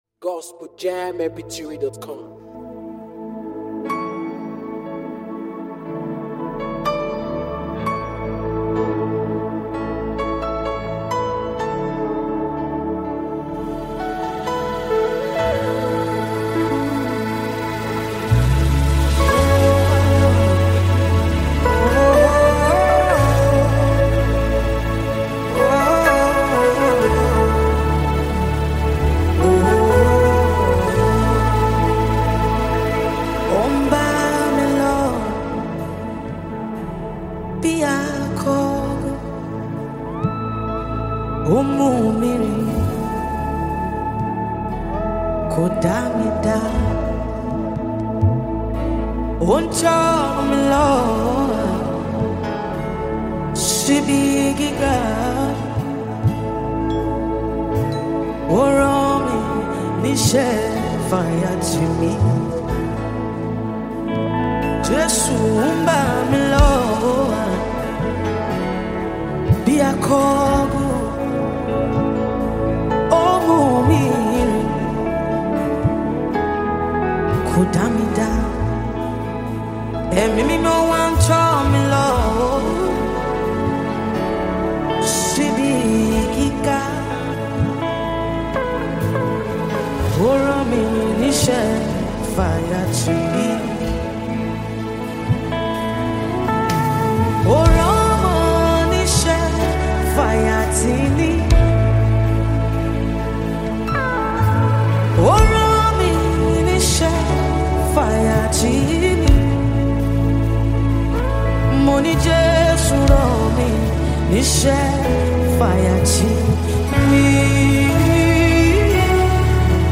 worship sound